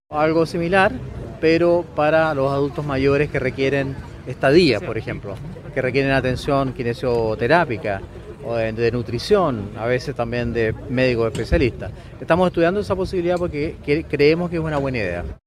Durante la ceremonia de puesta en marcha del nuevo Hospital Biprovincial Quillota-Petorca, que fue encabezada por el Presidente Sebastián Piñera, el Ministro de Salud Enrique Paris explicitó su interés por el proyecto de Centro del Bienestar para las Personas Mayores y sus Familias, que la Municipalidad de Quillota espera implementar en el edificio del Hospital San Martín, una vez que quede desocupado.
02-MINISTRO-PARIS-Internacion-y-Especialidades.mp3